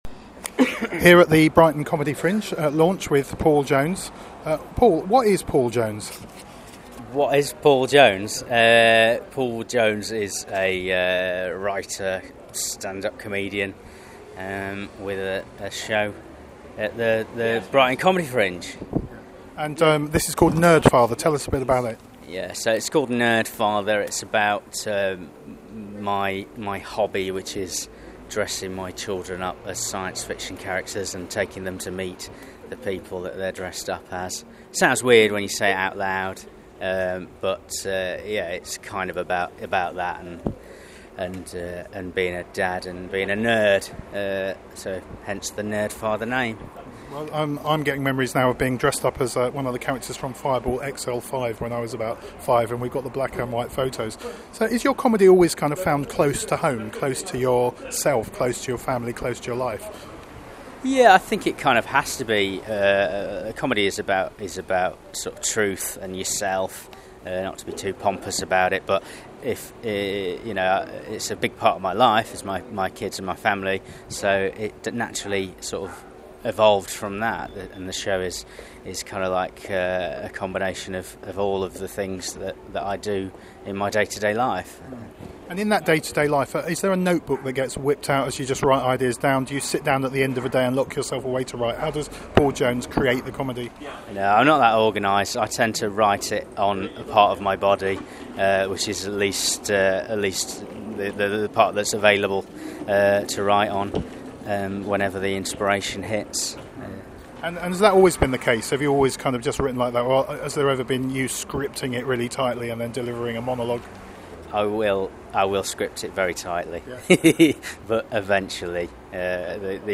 Brighton Comedy Fringe Audio 2014